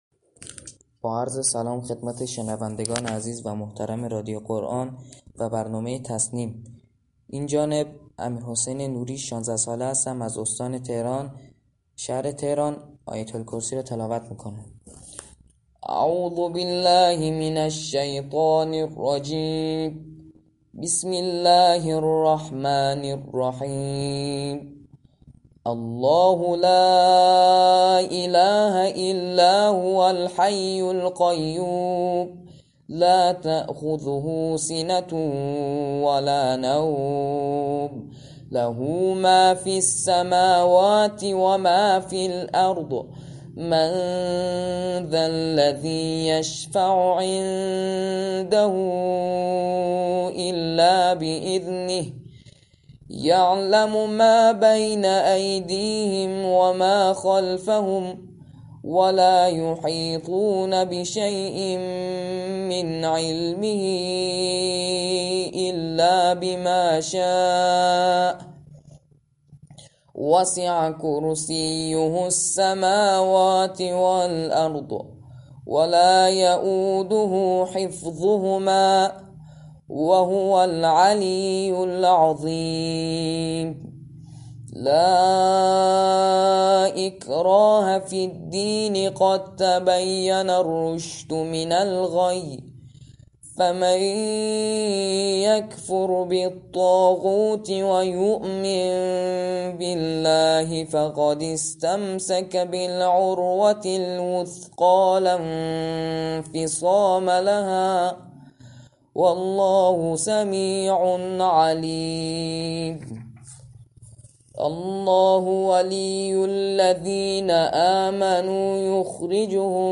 به گزارش پایگاه اطلاع رسانی رادیو قرآن؛ سردار محمد بهرامی معاون تبلیغات و روابط عمومی سازمان عقیدتی سیاسی انتظامی در گفتگو با برنامه والعصر رادیو قرآن گفت: طرحی كه با هدف ارتقاءسطح توانمندی حافظان و قاریان از سال 1399 در دستور كار قرار گرفته است جذب نخبگان قرآنی پایور و سرباز است كه این برنامه همچنان ادامه دارد و لذا نخبگان قرآنی كه علاقه مند به جذب به صورت پیمانی یا رسمی در سازمان انتظامی هستند و یا سربازانی كه تمایل دارند خدمت سربازی را در یگان‌های انتظامی بگذرانند می‌توانند با مراجعه به مراكز عقیدتی سیاسی‌ یگان‌های انتظامی سراسر كشور از این امتیاز ویژه برخوردار گردند.